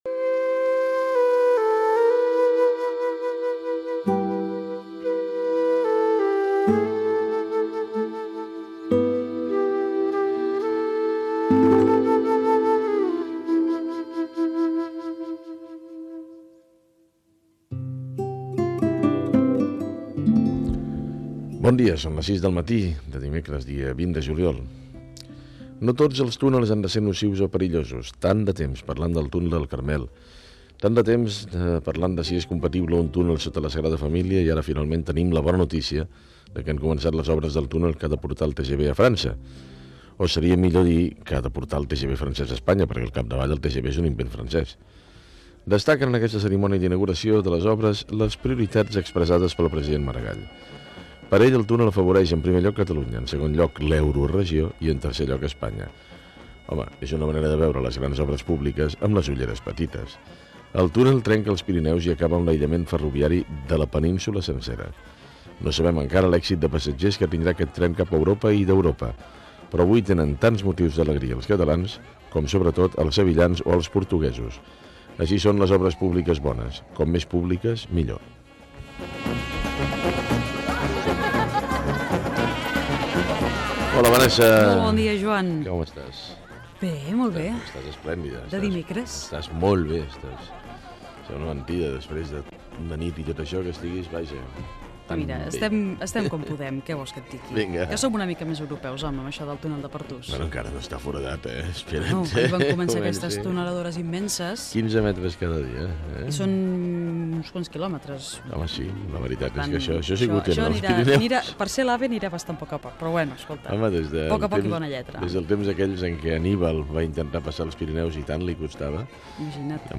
Hora, la construcció del túnel dels Pirineu, perquè el Tren de Gran Velocitat de França arribi a Catalunya. Diàleg dels presentadors.
Informatiu